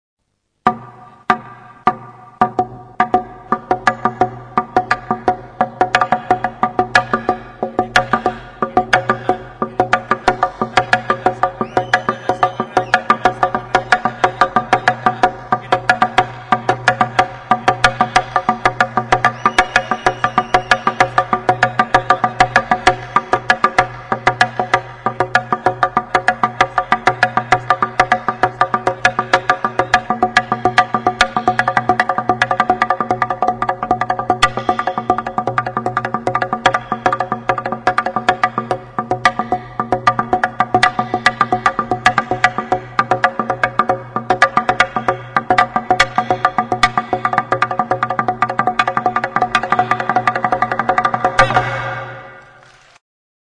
Idiophones -> Struck -> Directly
KIRIKOKETA JOALDIA. Baztango 'jo ala jo' kirikoketa taldea. Hernani, 1999.